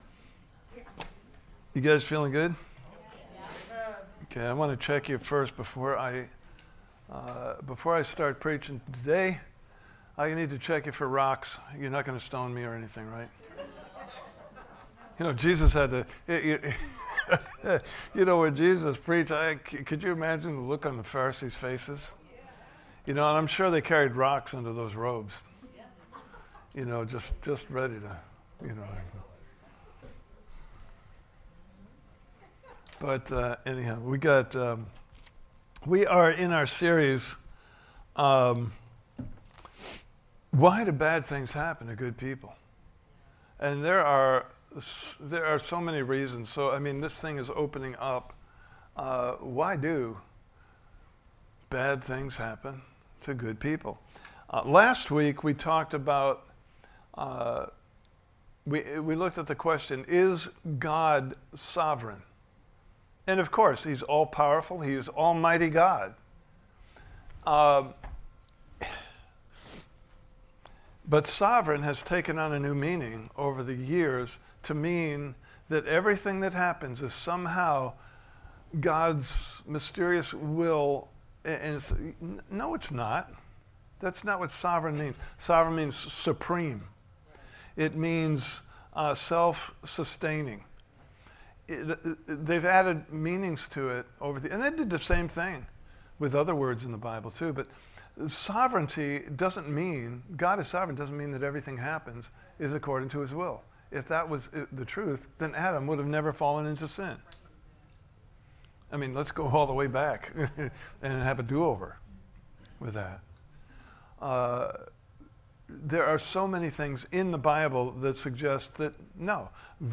Series: Why Do Bad Things Happen To Good People? Service Type: Sunday Morning Service « Part 1: Is God Sovereign?